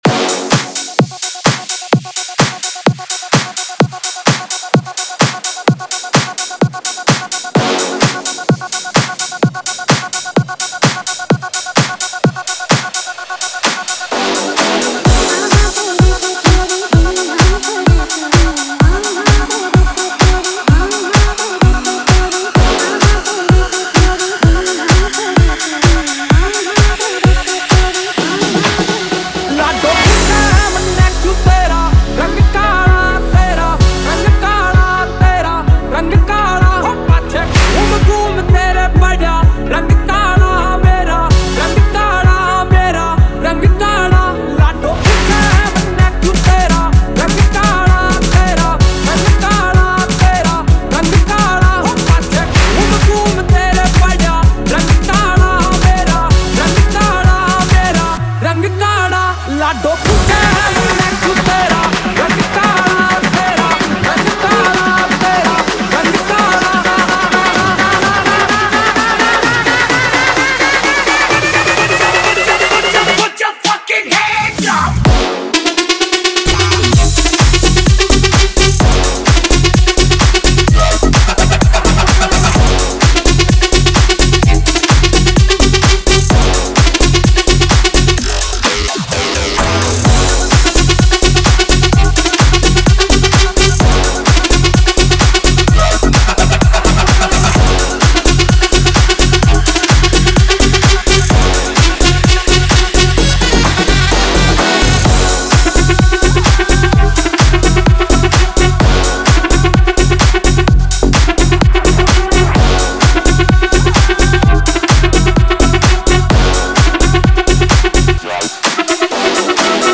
Category : Latest Dj Remix Song